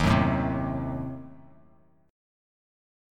Ebdim7 chord